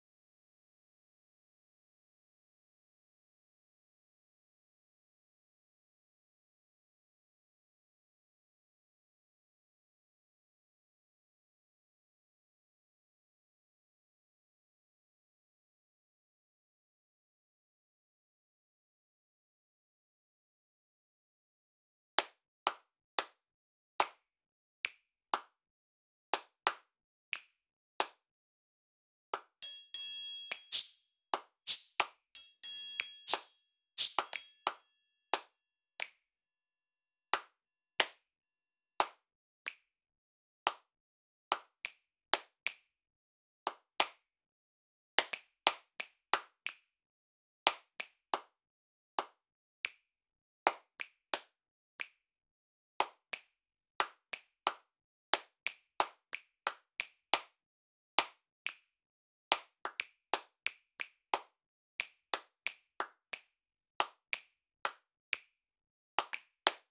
90 BPM
Coffee Shop percussion 2 68385_Coffee Shop_2.wav